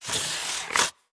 Skates and Ice Sudden Stop.wav